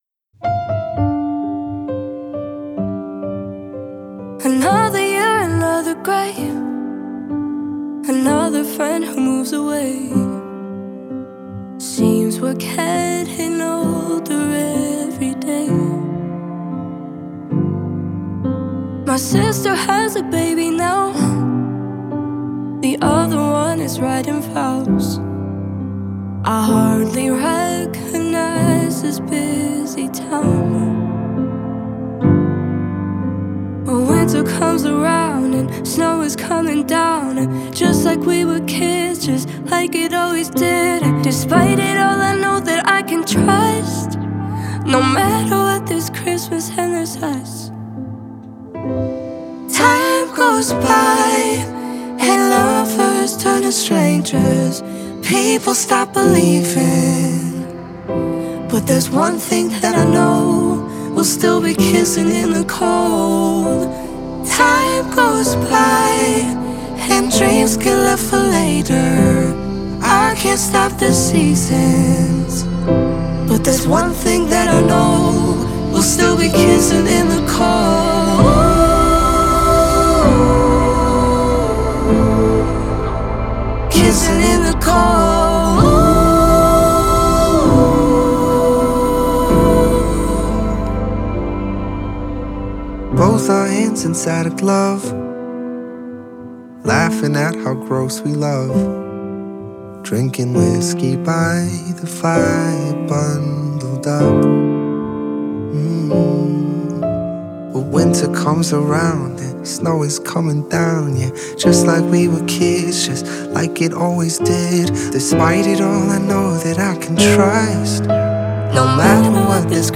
это нежная поп-баллада